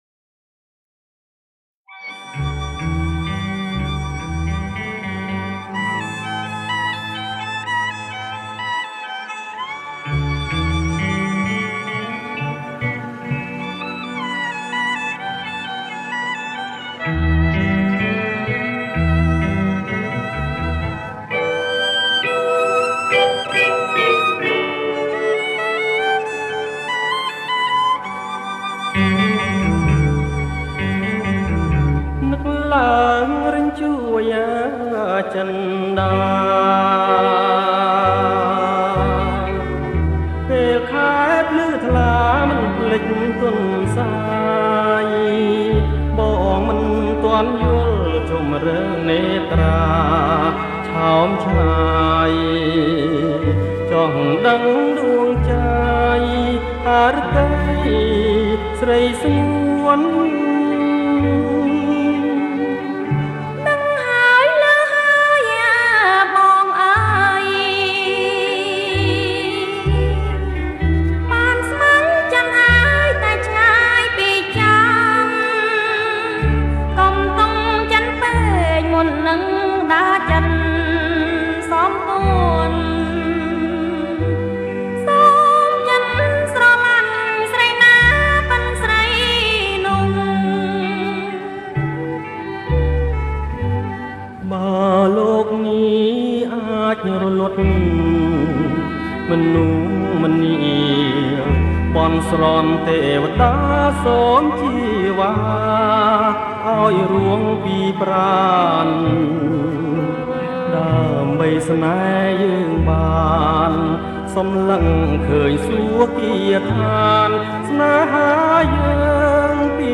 ប្រគំជាចង្វាក់ Blue